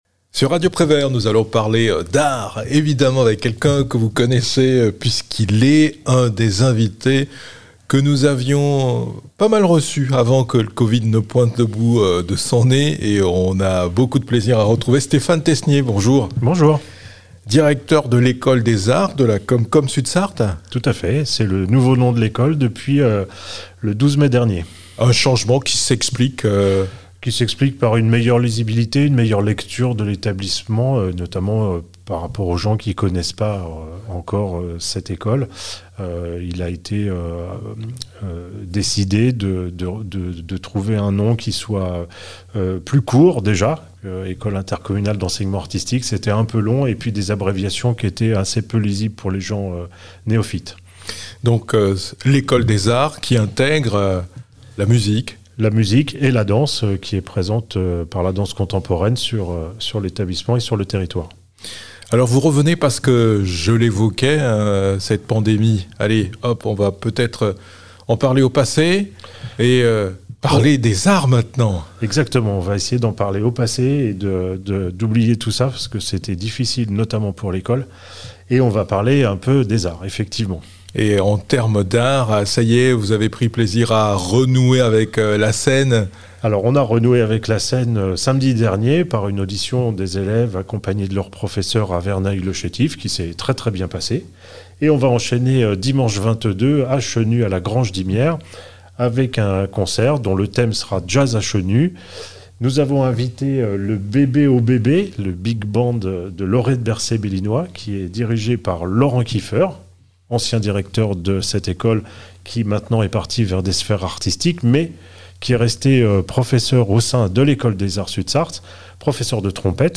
Jazz à Chenu avec le BBOBB Big Band le 22 mai 2022